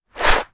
bow-arrow.ogg